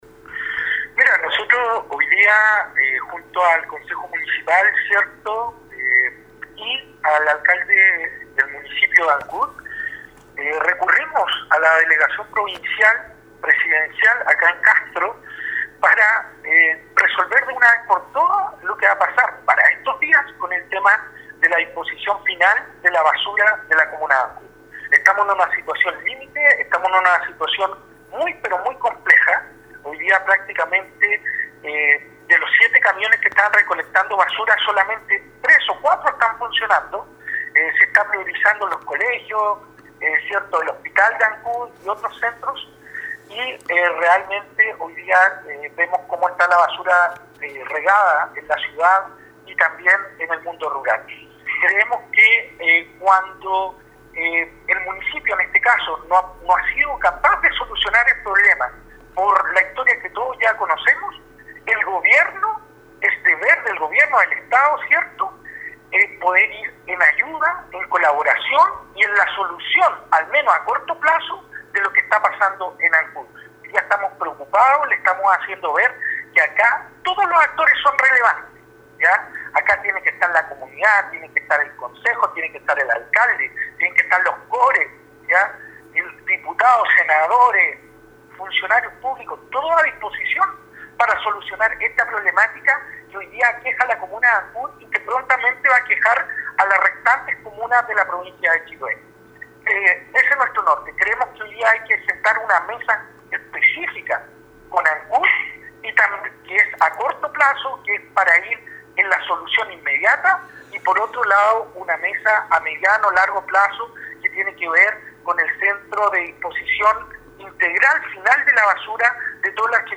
Dialogamos con el Consejero Regional Andrés Ojeda acerca del tenor de esta reunión en castro